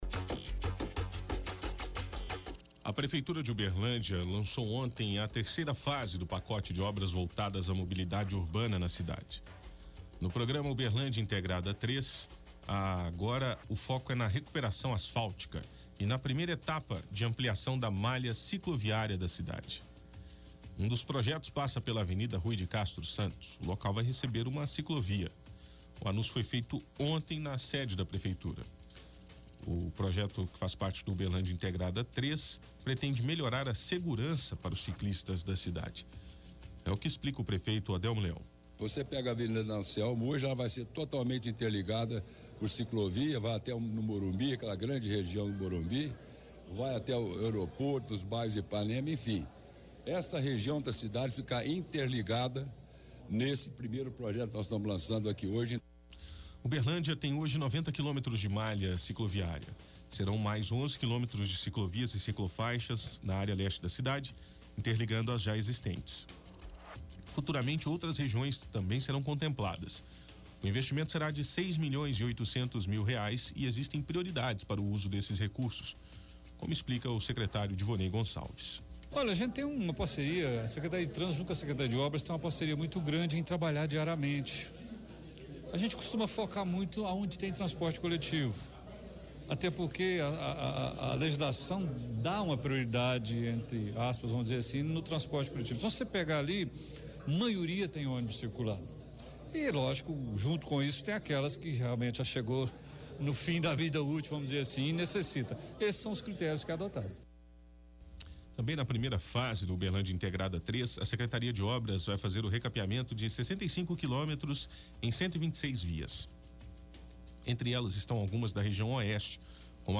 -O Projeto pretende melhorar a segurança para os ciclistas da cidade. É o que explica o *prefeito Odelmo Leão*.